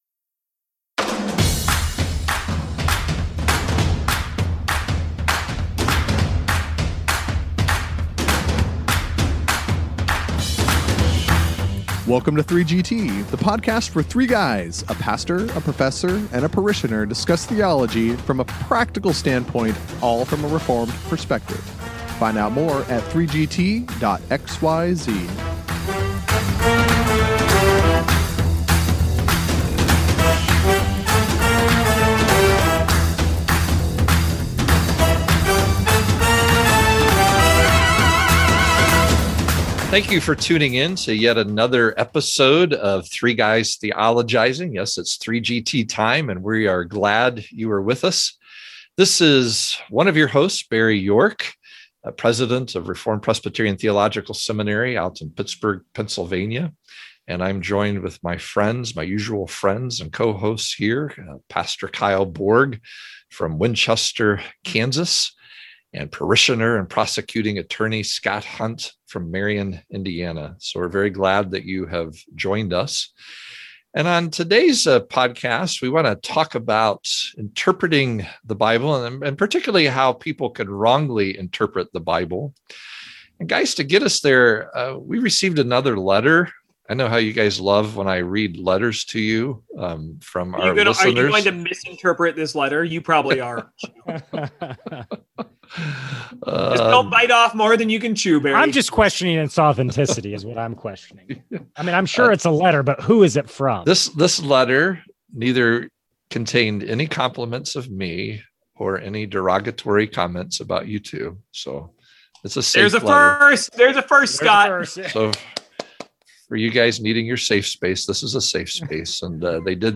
The professor starts reading another listener’s letter, causing the pastor and parishioner to flinch.